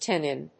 音節tan・nin 発音記号・読み方
/tˈænɪn(米国英語)/